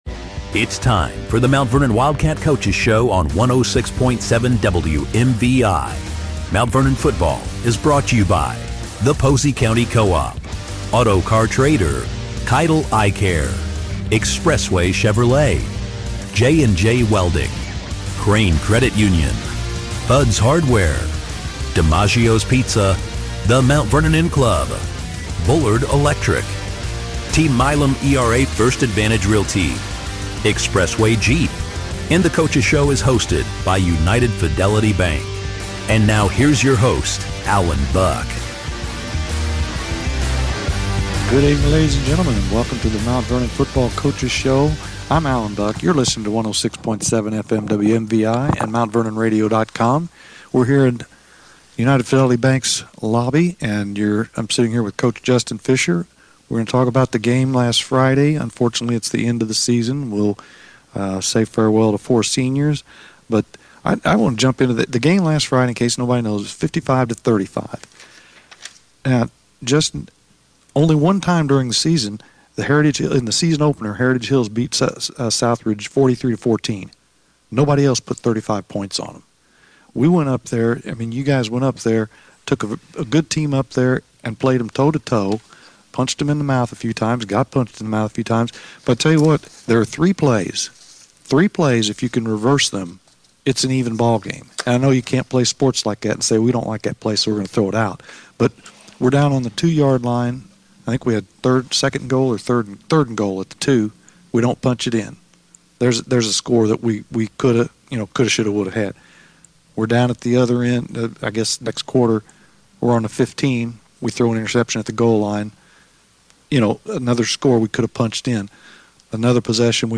The show comes your way live each Monday from United Fidelity Bank at 5:30.